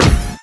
pl_step3-h.wav